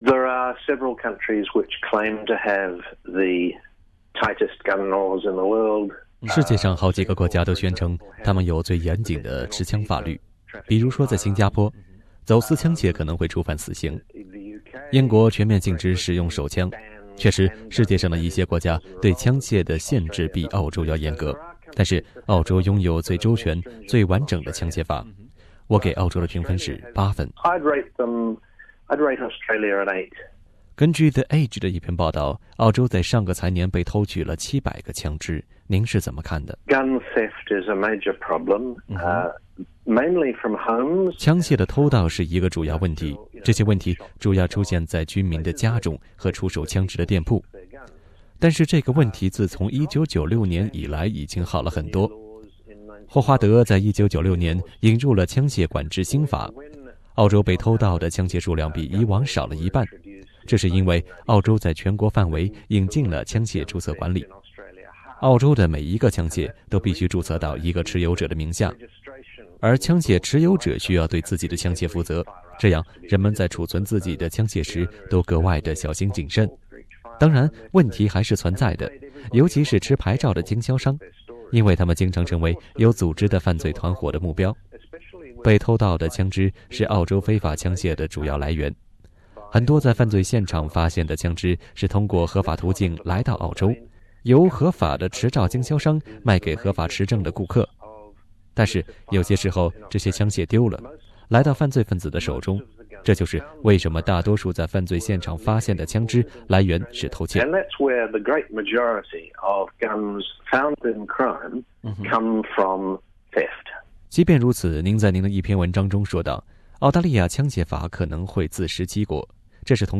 Source: AAP SBS 普通话电台 View Podcast Series Follow and Subscribe Apple Podcasts YouTube Spotify Download (4.03MB) Download the SBS Audio app Available on iOS and Android 在美国的历史上，人们认为持枪是公民自由而不是特权。